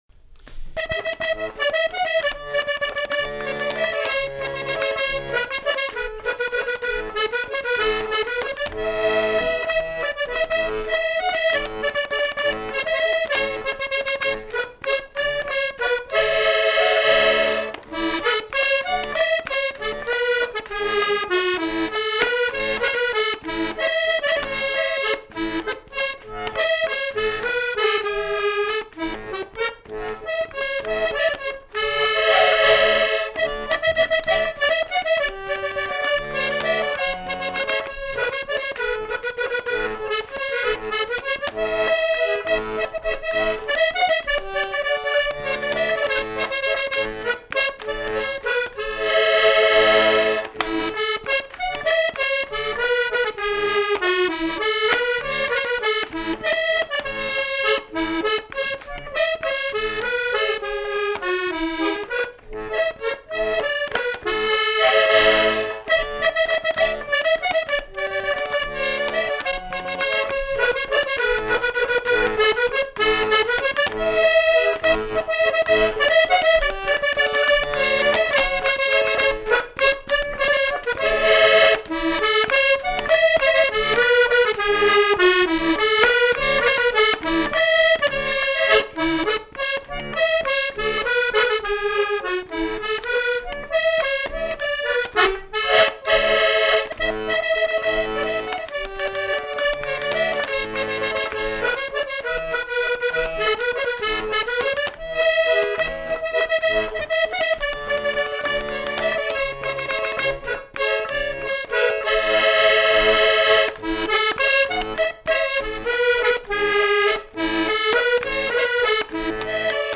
Aire culturelle : Périgord
Lieu : Beaumont-du-Périgord
Genre : morceau instrumental
Instrument de musique : accordéon chromatique
Danse : valse